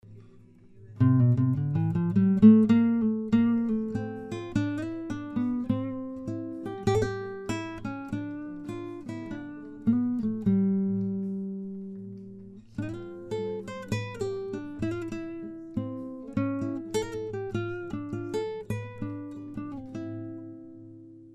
guitarra criolla mayor do folclore